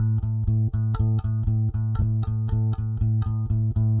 Live Bass Guitar Loops " Bass loop 120 bpm rock eights a1
描述：小套的贝斯循环典型的摇滚八音，速度为120 bpm不同的音符（在文件名后面）。循环完美。有压缩器的线型低音信号。指点迷津。
Tag: 手指 摇滚 现场 吉他 巴萨吉他 120BPM 八分 低音 不断